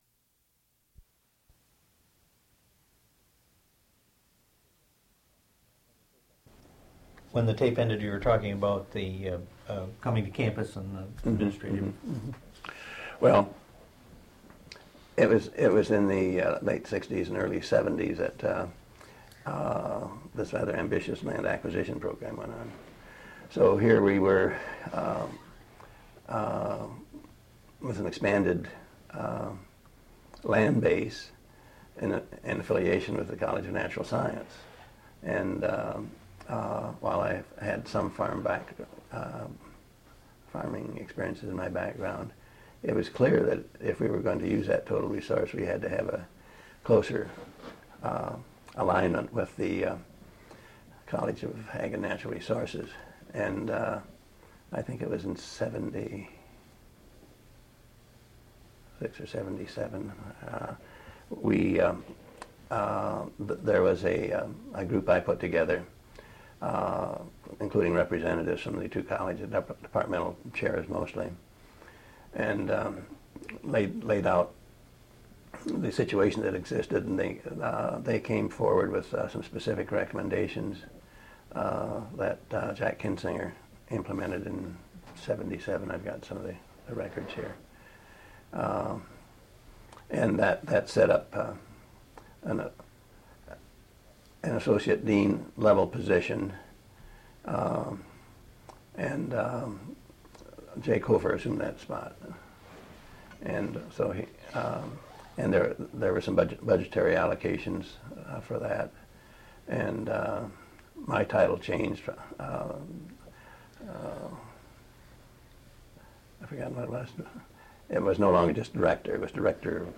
Original Format: Audiocassettes
Sesquicentennial Oral History Project